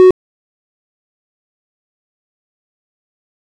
Paddle.wav